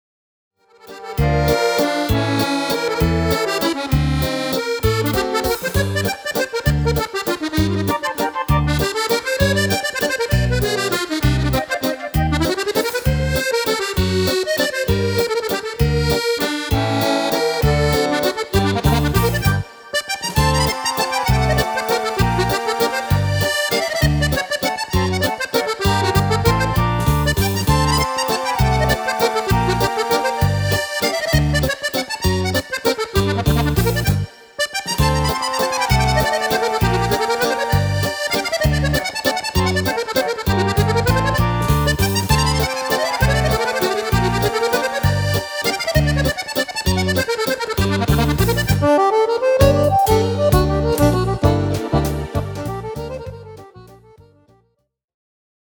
Valzer
Fisarmonica